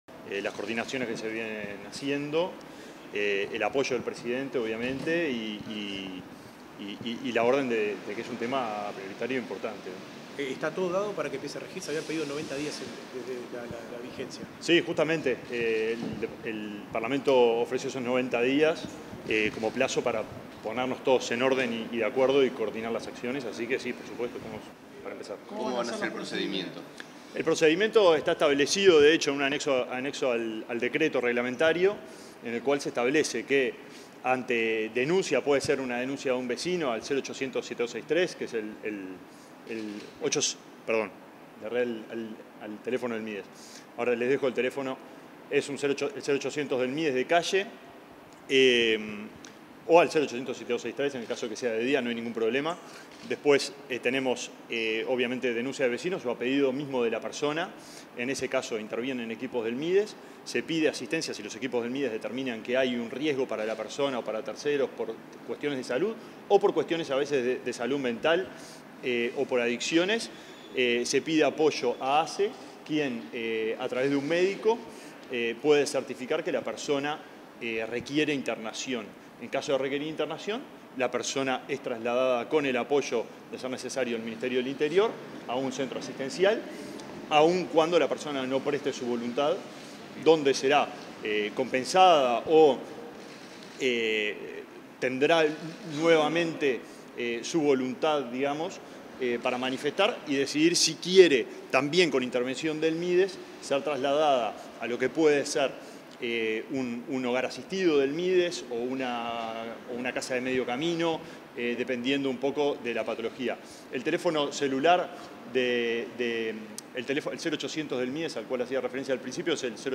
Declaraciones del ministro de Desarrollo Social, Alejandro Sciarra
Declaraciones del ministro de Desarrollo Social, Alejandro Sciarra 22/08/2024 Compartir Facebook X Copiar enlace WhatsApp LinkedIn Tras una reunión con el presidente de la República, para implementar la ley de internación compulsiva, este 22 de agosto, el ministro de Desarrollo Social, Alejandro Sciarra, realizó declaraciones a la prensa.